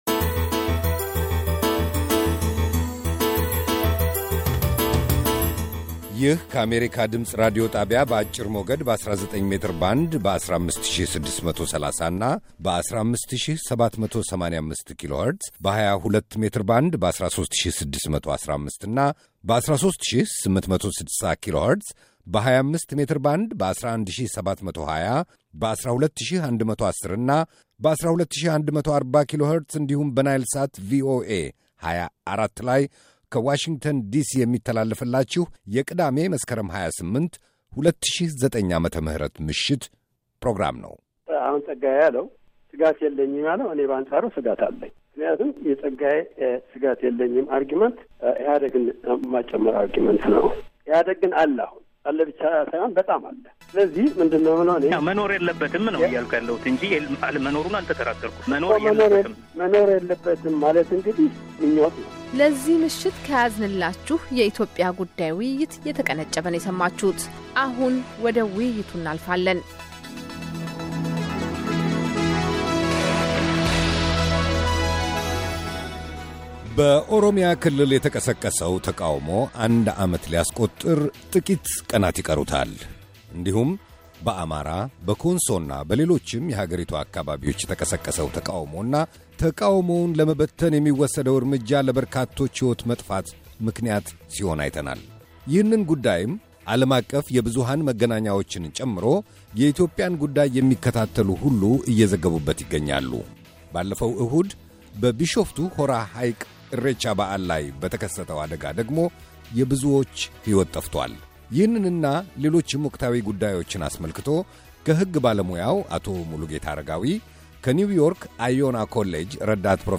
ውይይት ከሦስት በኢትዮጵያ ወቅታዊ ጉዳይ (ክፍል ሁለትና የመጨረሻ)